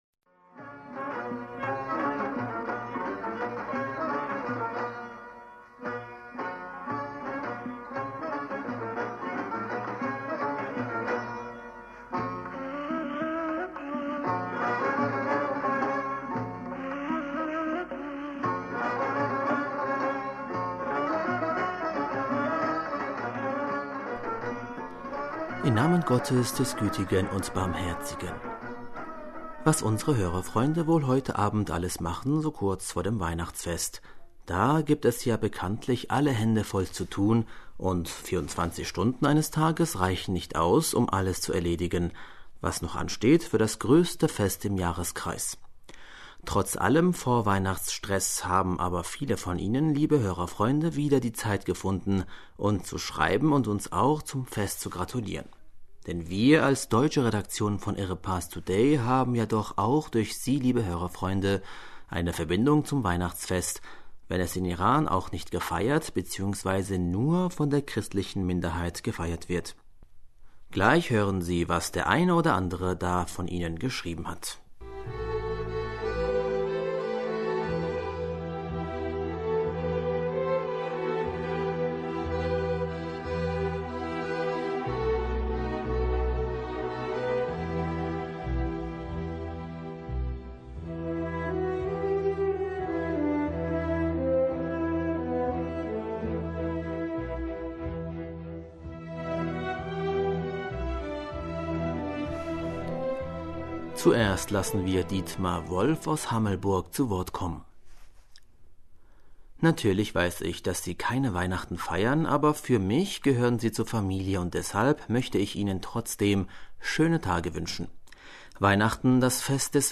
Hörerpostsendung am 23. Dezember 2018 - Bismillaher rahmaner rahim - Was unsere Hörerfreunde wohl heute Abend alles machen, so kurz vor dem Weihna...